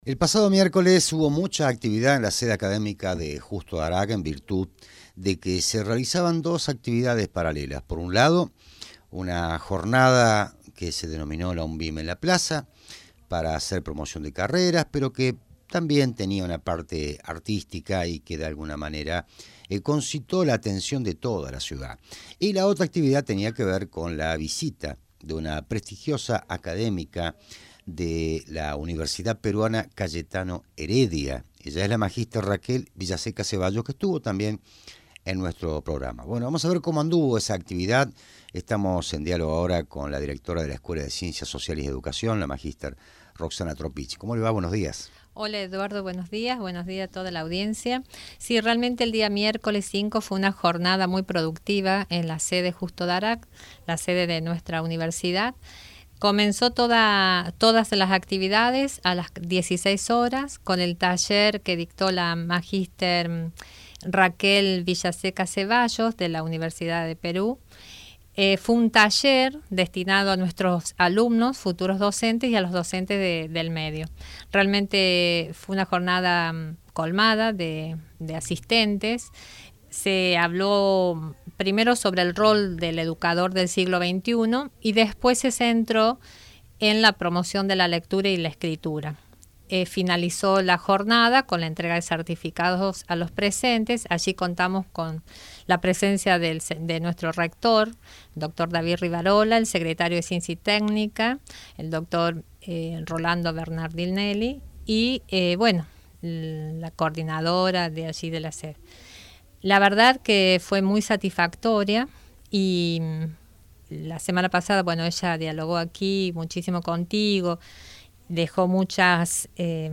entrevistada en el programa “Agenda Universitaria”